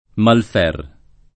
vai all'elenco alfabetico delle voci ingrandisci il carattere 100% rimpicciolisci il carattere stampa invia tramite posta elettronica codividi su Facebook Malfer [ malf $ r ] cogn. — in qualche famiglia, alterato in m # lfer